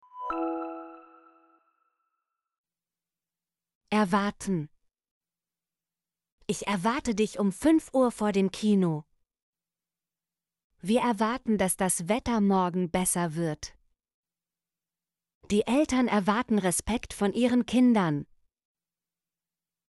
erwarten - Example Sentences & Pronunciation, German Frequency List